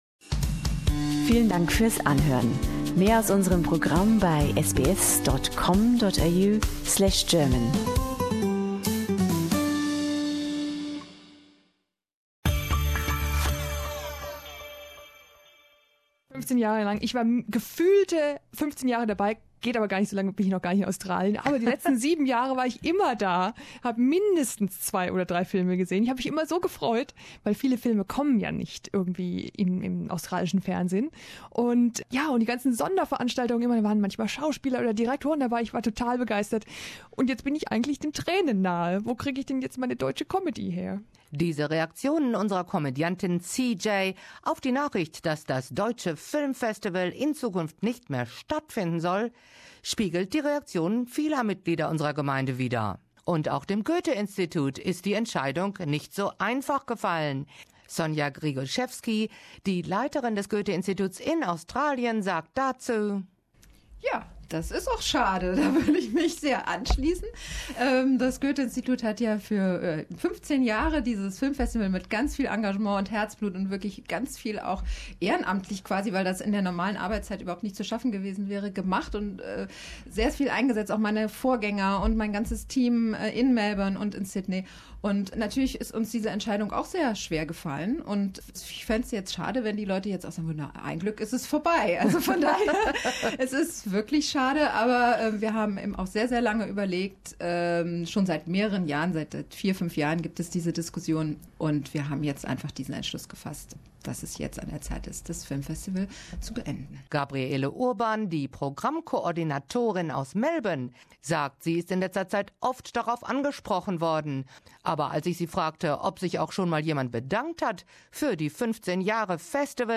Viele Australier, ob deutscher oder anderer Herkunft, kennen das Goethe Institut vor allem durch die Filmfestivals, die über die letzten 15 Jahre veranstaltet wurden. In diesem Bericht finden wir heraus, warum es in Zukunft nicht mehr stattfinden wird, was stattdessen geplant ist, und werfen einen Blick zurück auf die Highlights.